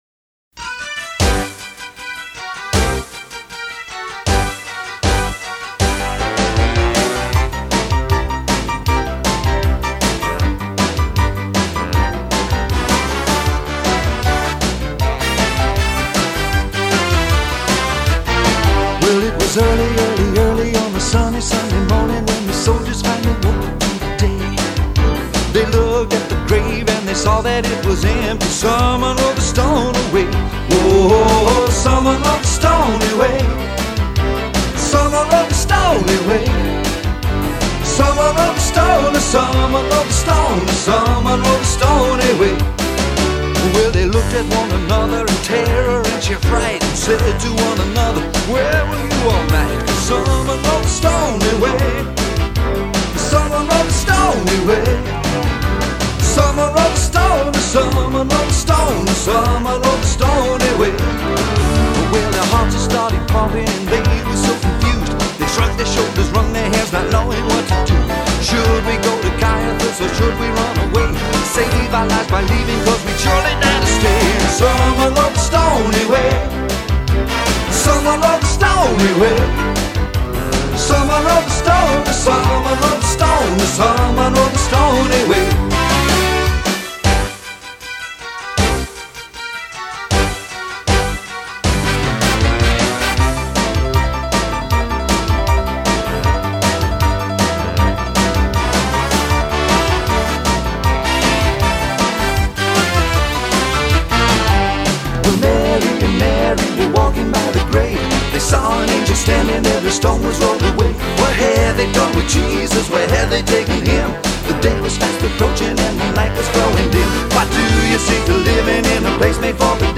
vocals